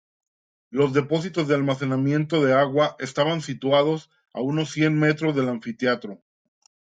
an‧fi‧te‧a‧tro
/anfiteˈatɾo/